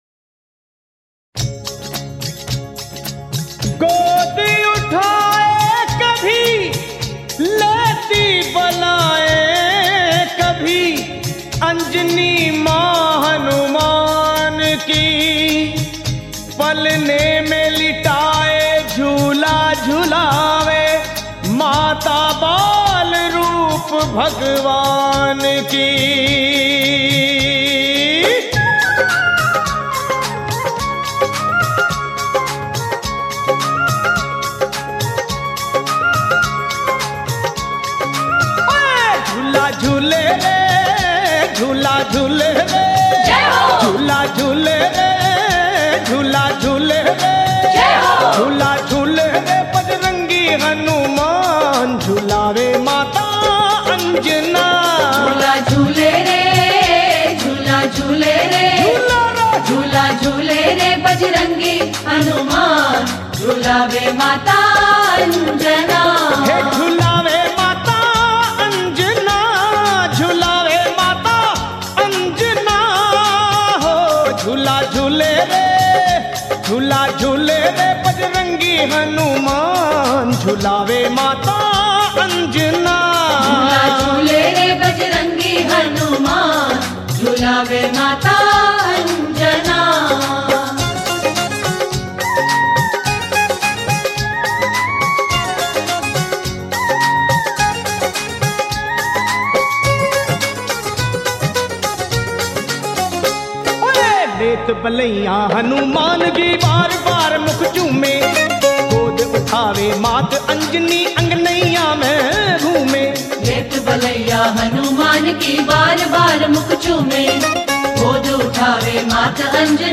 Rajasthani Songs
Balaji Bhajan